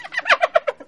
c_hyena_atk2.wav